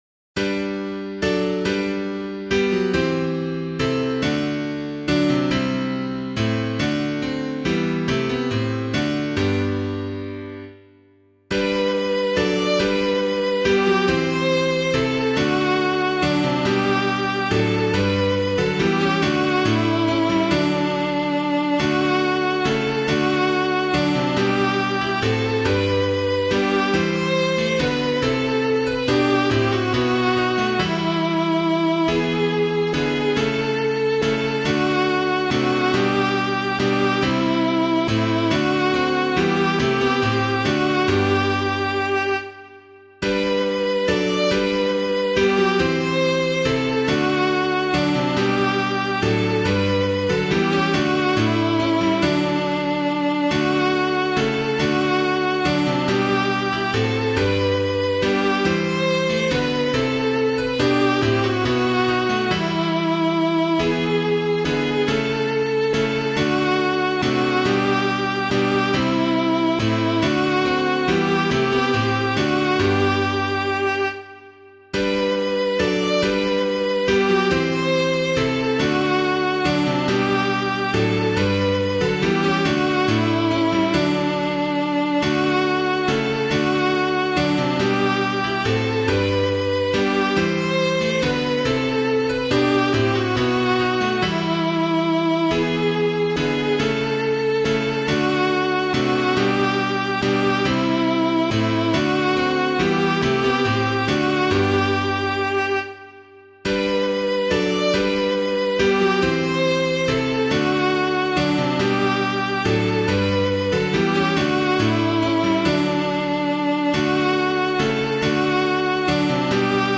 Christ Is Made the Sure Foundation [Neale - WESTMINSTER ABBEY] - piano.mp3